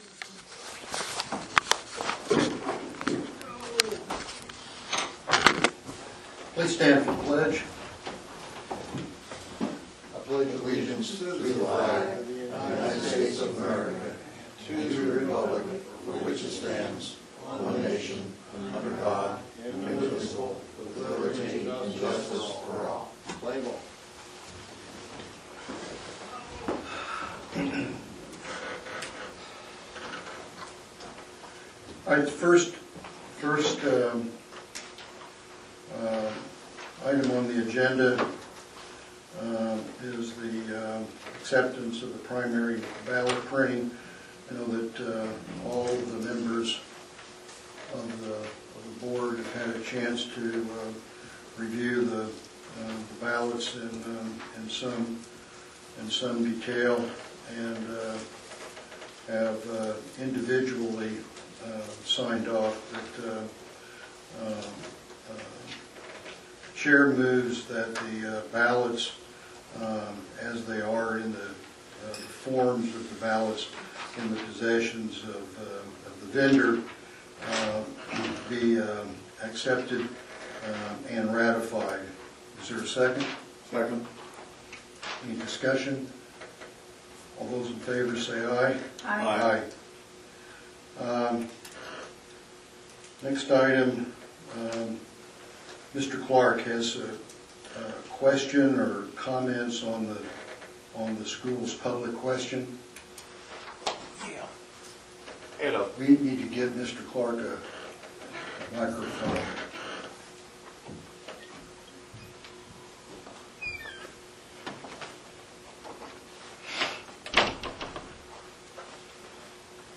Agenda Mar 12 2024 Election Board Meeting